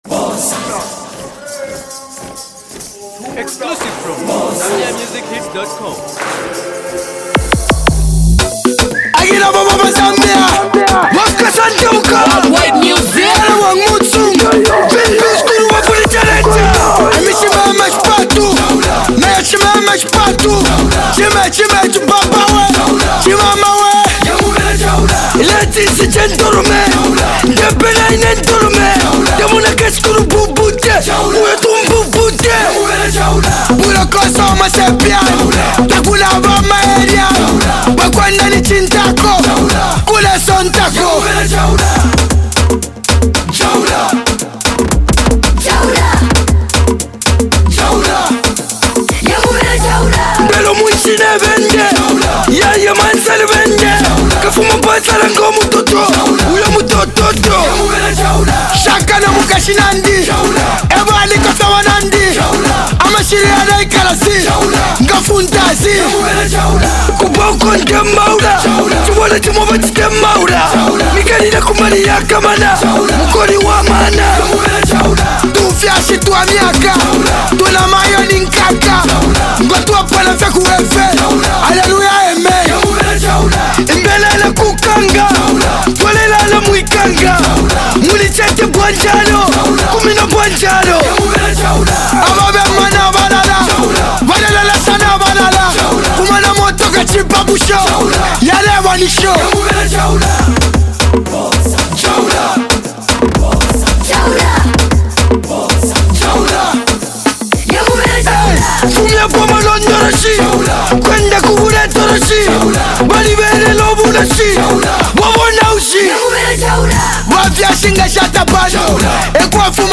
rap Duo